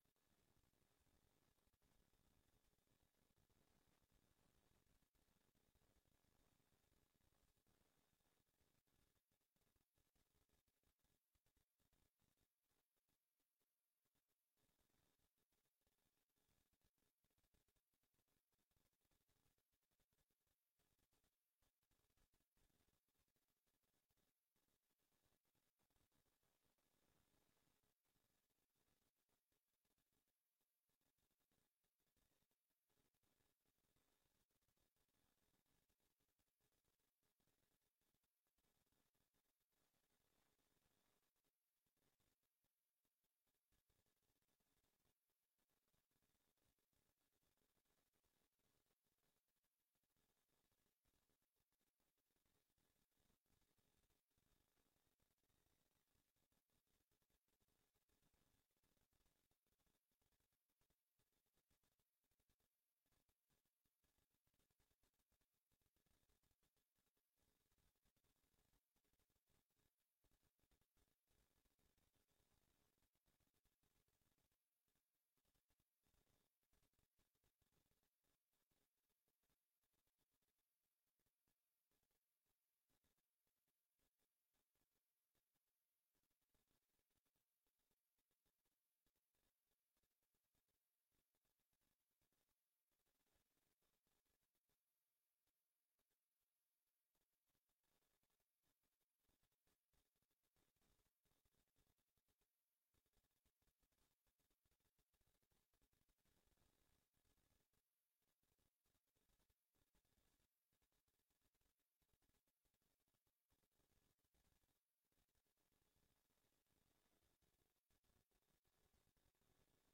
gemeenteraad 22 mei 2024 16:15:00, Gemeente Groningen
Download de volledige audio van deze vergadering